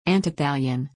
PRONUNCIATION:
(an-tee-THAY-lee-uhn)